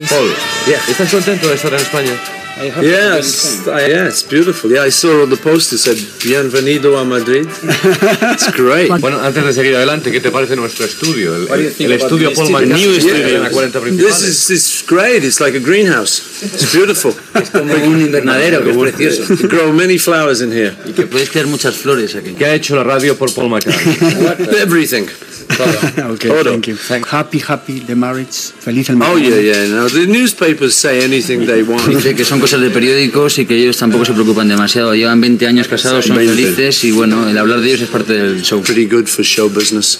Entrevista al música Paul McCartney quan va inaugurar els nous estudis de Los 40 Principales, a Madrid